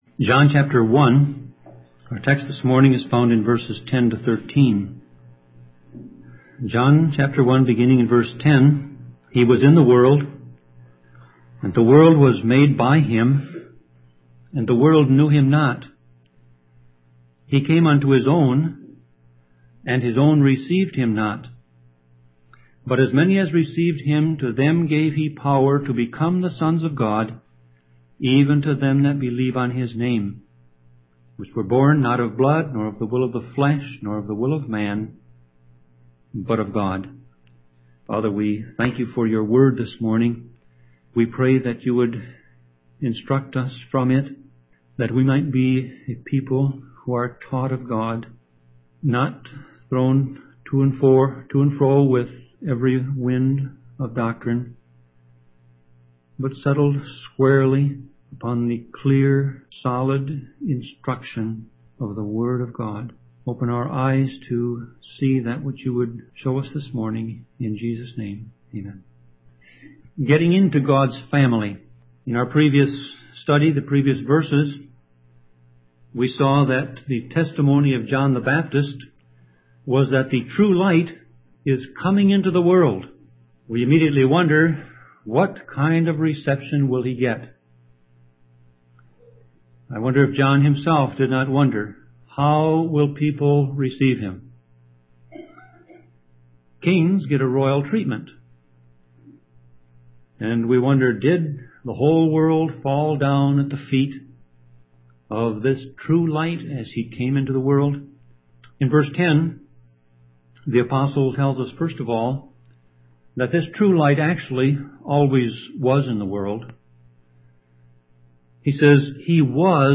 Series: Sermon Audio